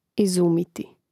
izùmiti izumiti